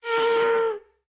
Minecraft Version Minecraft Version snapshot Latest Release | Latest Snapshot snapshot / assets / minecraft / sounds / mob / happy_ghast / ambient3.ogg Compare With Compare With Latest Release | Latest Snapshot